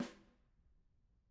Snare2-HitSN_v3_rr2_Sum.wav